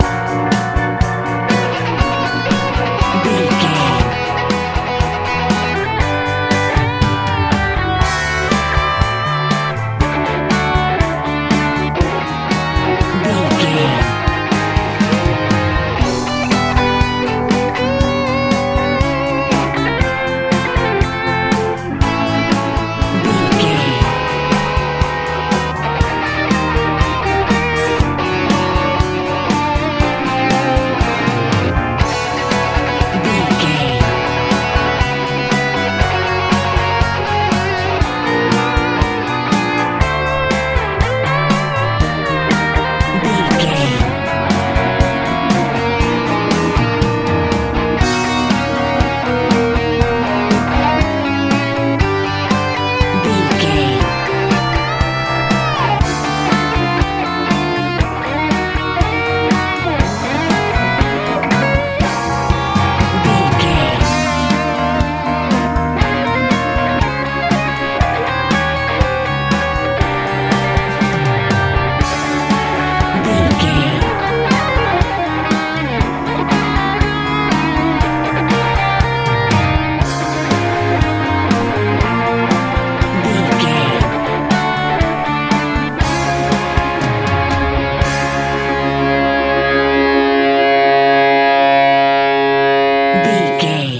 Ionian/Major
driving
energetic
lively
bass guitar
drums
organ
electric guitar
groovy